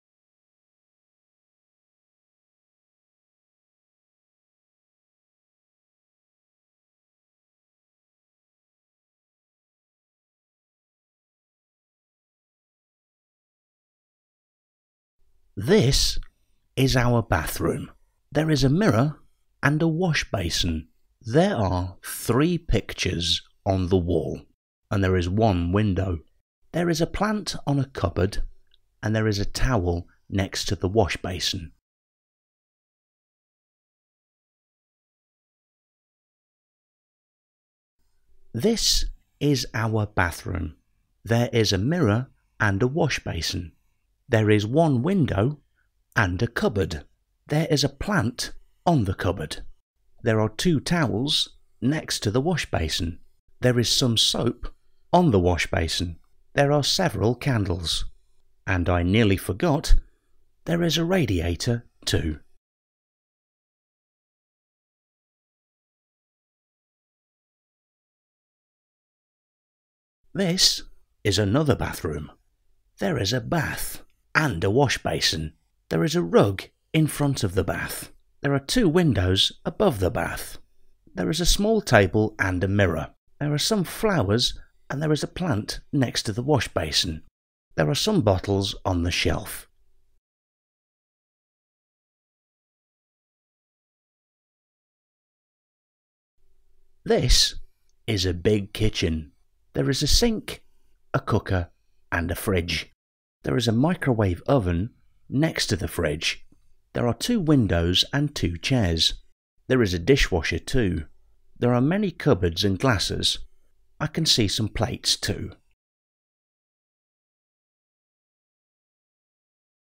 What rooms are mentioned? What does the man say about each?